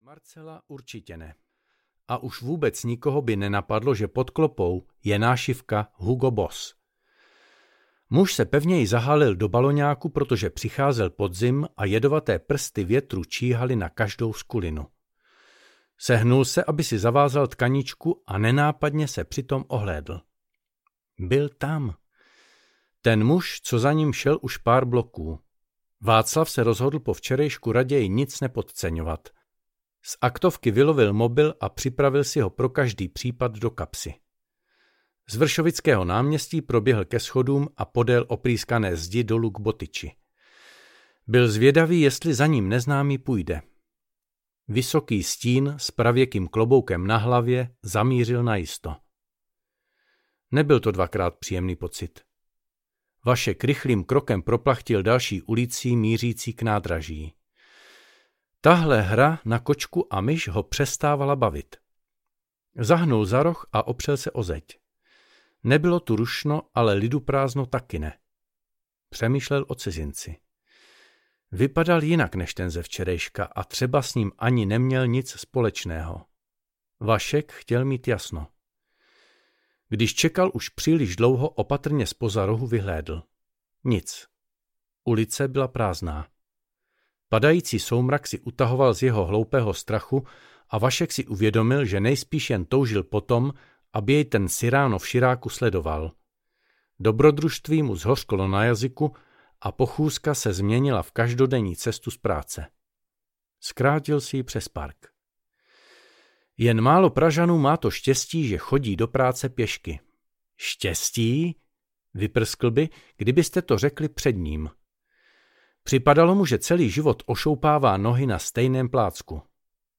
Zlatý kříž audiokniha
Ukázka z knihy